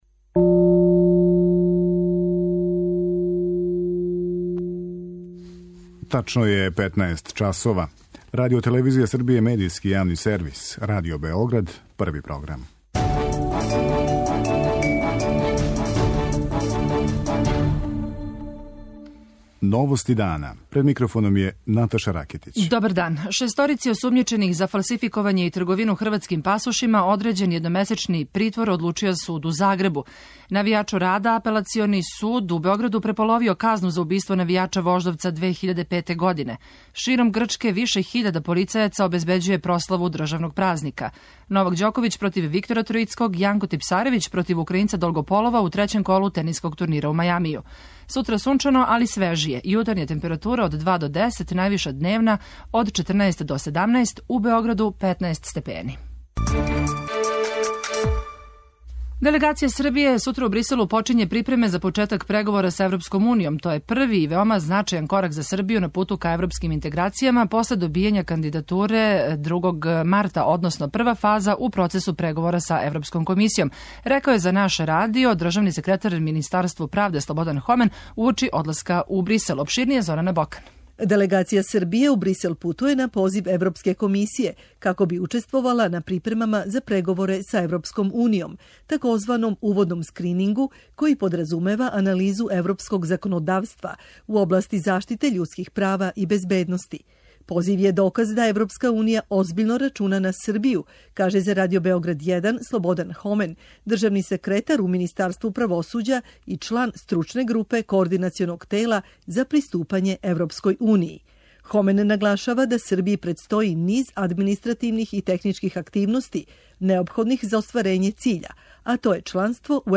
Делегација Србије сутра у Бриселу почиње припреме за почетак преговора с Европском унијом. То је први и веома значајан корак за Србију на путу ка европским интеграцијама после добијања кандидатуре почетком марта, односно прва фаза у процесу преговора с Европском комисијом, каже за Радио Београд 1 државни секретар у министарсту правде Слободан Хомен уочи одласка у Брисел.